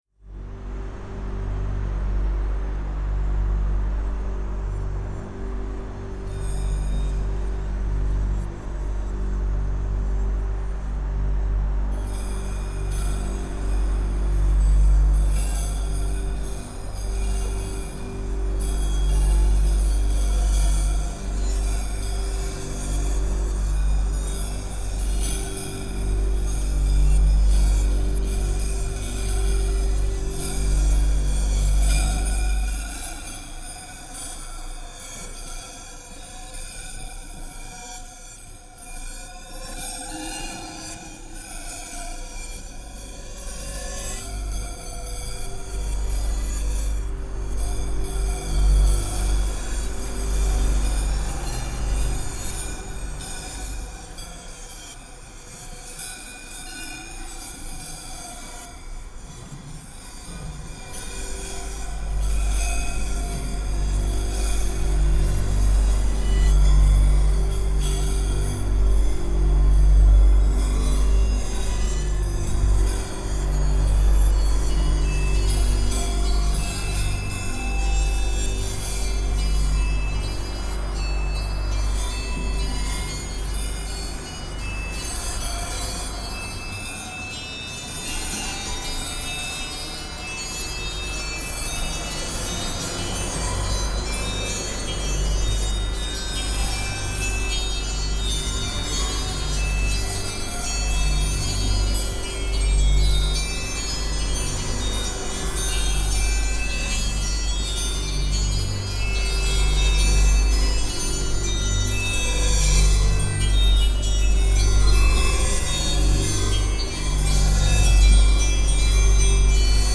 Gold_Making_Machine_Ambiance
RitualGold_Ambiance.mp3